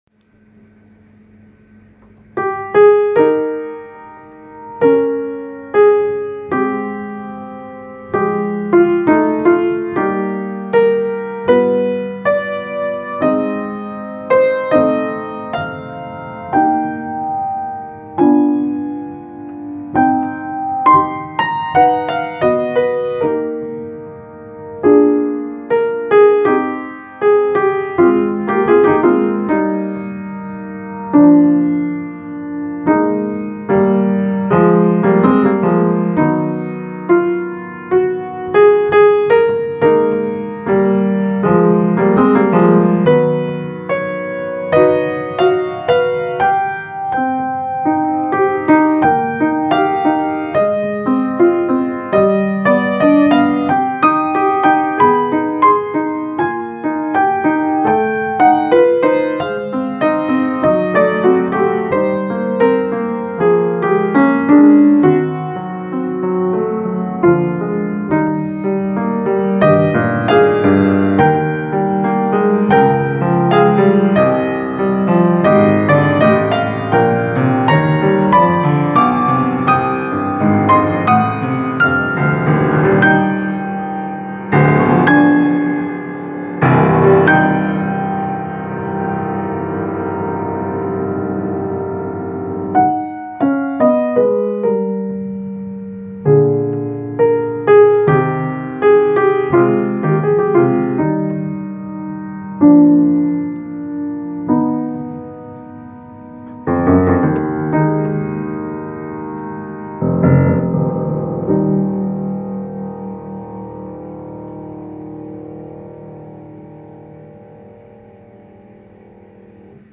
Piano Music, Solo Keyboard
Prelude No.6 - Live Performance
Oh hi again Here's no.6, kind of an ABA form, Eb - Cm - Eb. I wanted to write something conservative this time, keeping the harmony romantic and all.
😄 The tempo marking probably shouldn't be lento.